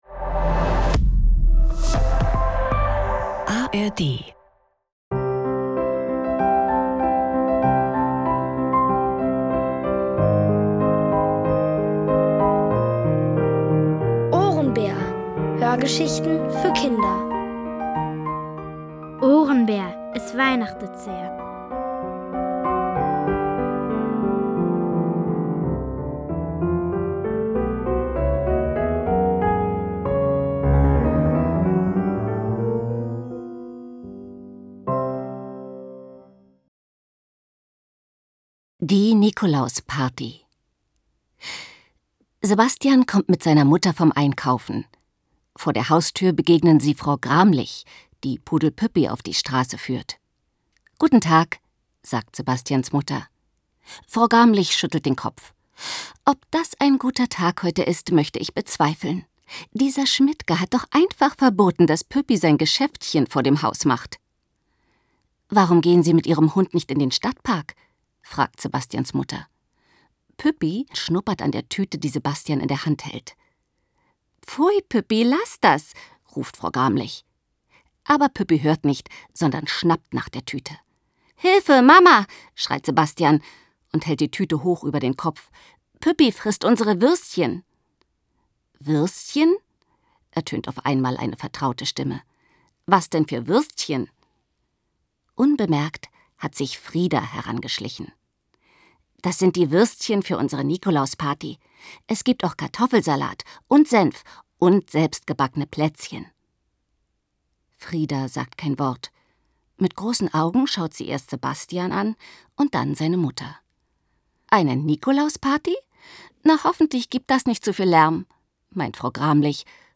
OHRENBÄR – es weihnachtet sehr | Die komplette Hörgeschichte!